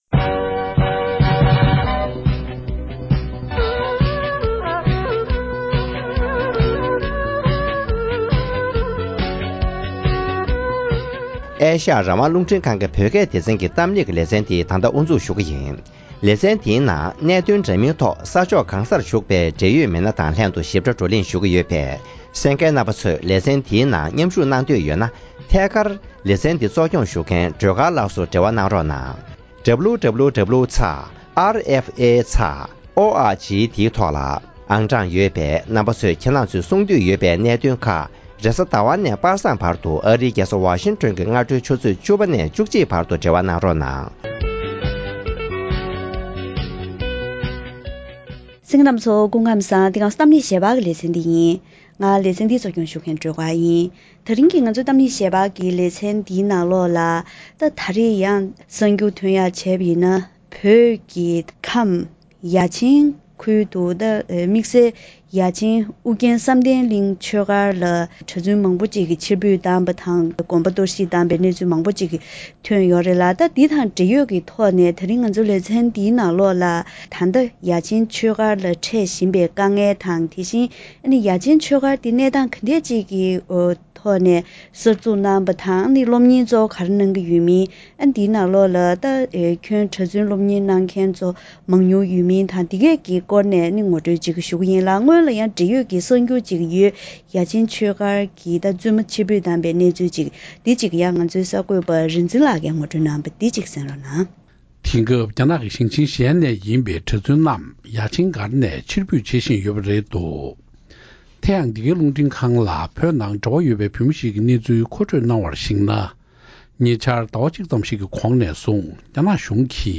ཡ་ཆེན་ཆོས་སྒར་གྱི་འདས་པ་དང་ད་ལྟའི་གནས་སྟངས་དེ་བཞིན་དམ་བསྒྲགས་བྱེད་བཞིན་པ་སོགས་ཀྱི་ཐད་གླེང་མོལ་ཞུས་པ།